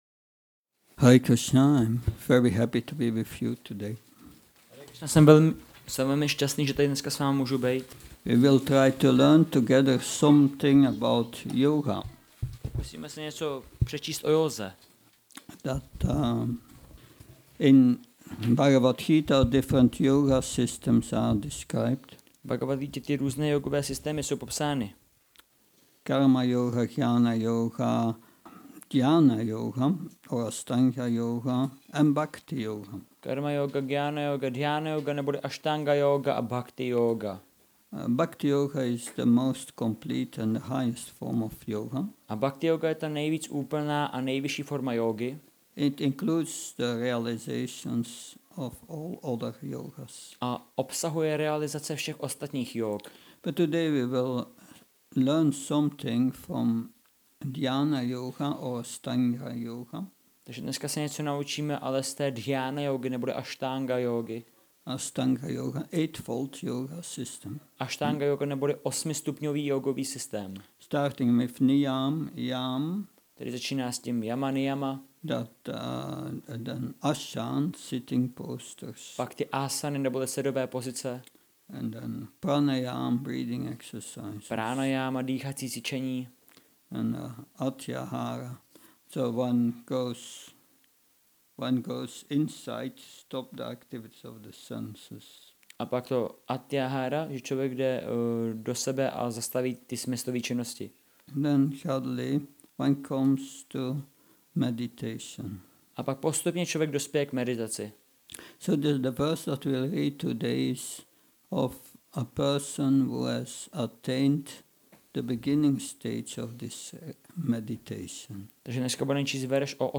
Šrí Šrí Nitái Navadvípačandra mandir
Přednáška BG-6.19